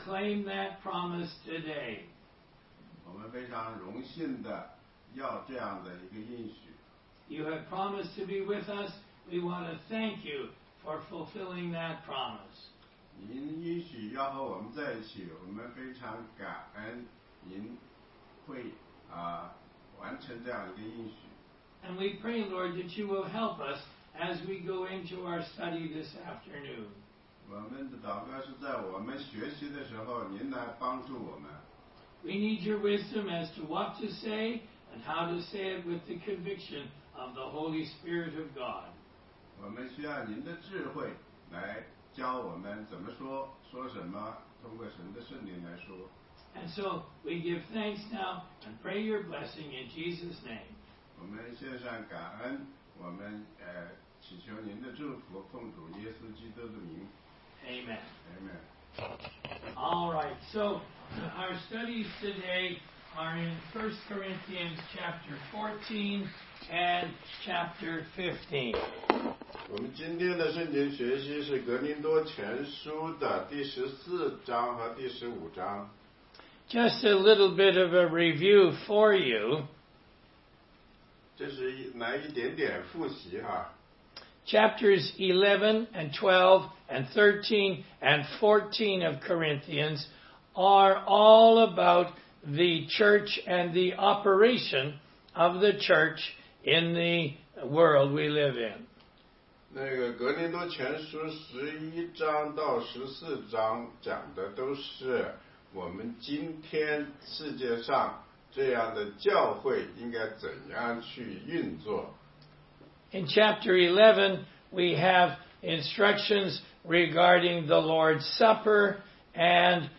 16街讲道录音 - 哥林多前书15章1-11节：保罗所传的福音是什么？